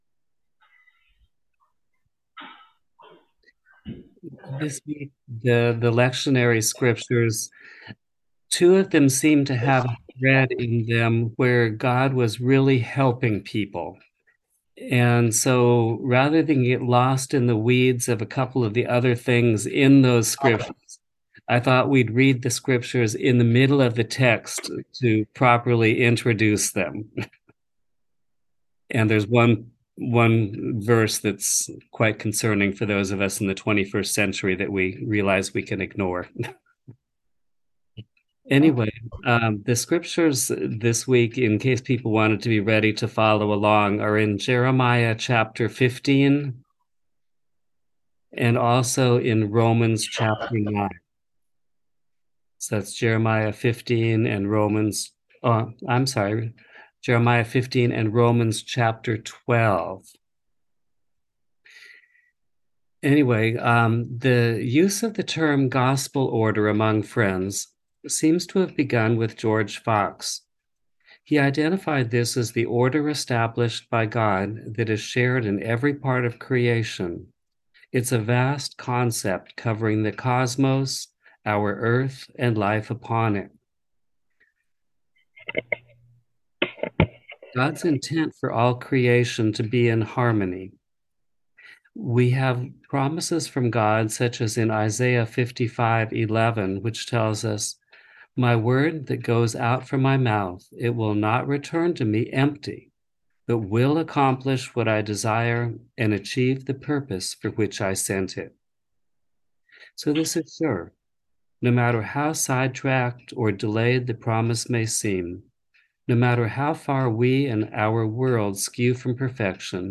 Message for September 3, 2023